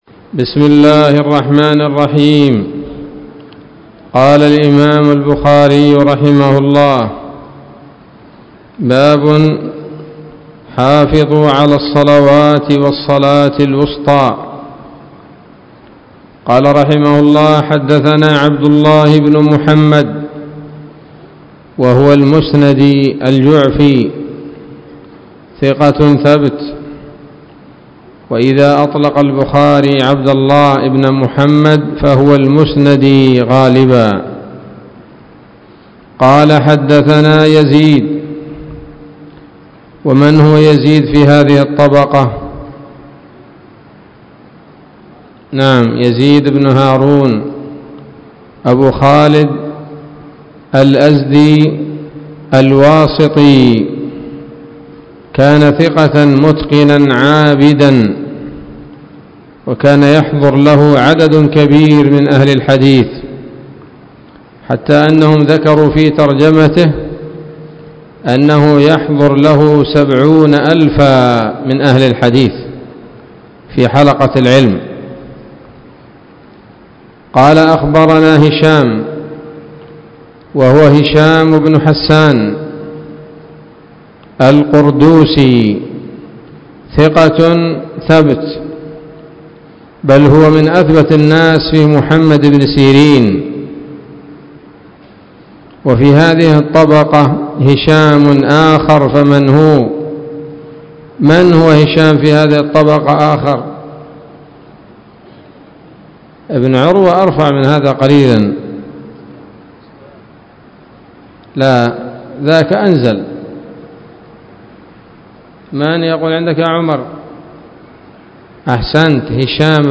الدرس الخامس والثلاثون من كتاب التفسير من صحيح الإمام البخاري